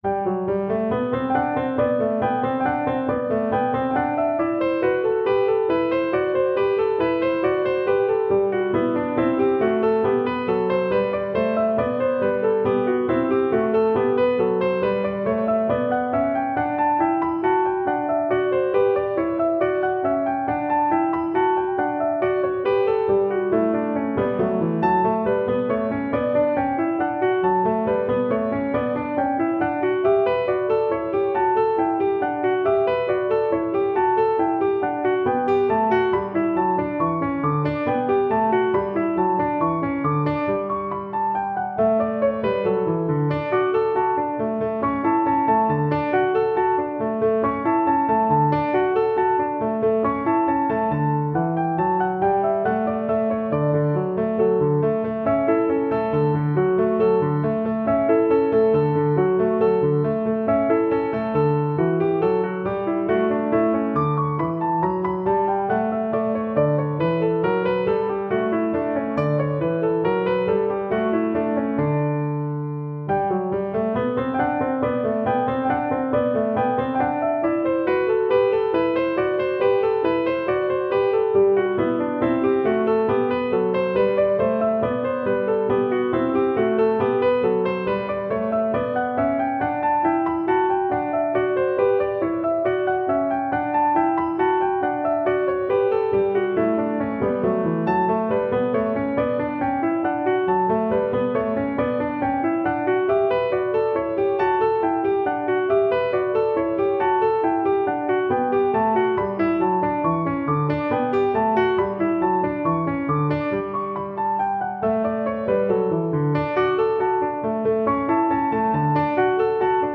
• Duet (Violin / Viola)